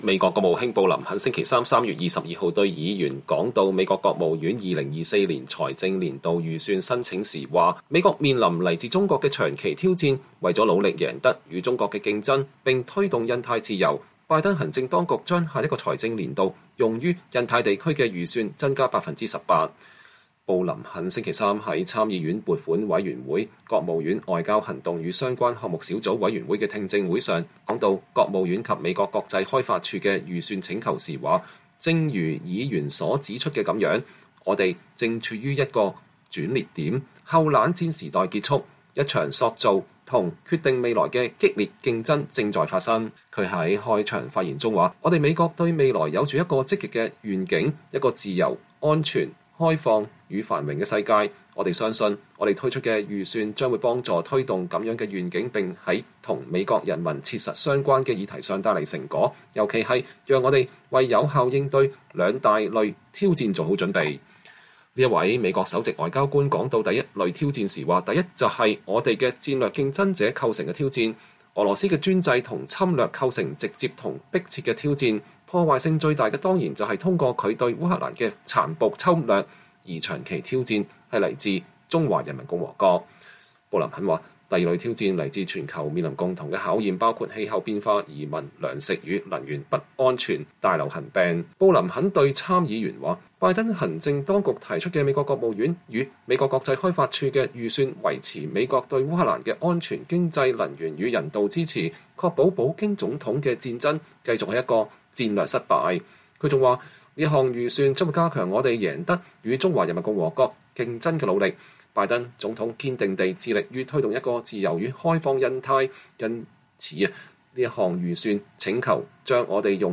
美國國務卿布林肯在參議院撥款委員會的相關小組委員會就拜登總統為國務院2024財年的預算請求作證。(2023年3月22日)